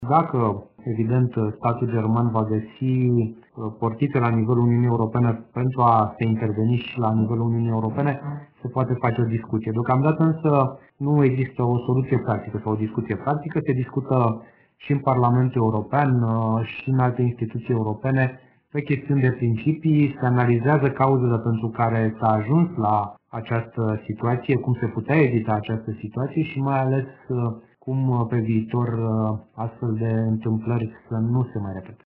În ceea ce priveşte scandalul Wolkswagen, Cristian Buşoi spune că Uniunea Europeană va întări standardele. Europarlamentarul Cristian Buşoi spune că problema trebuie rezolvată de către compania germană.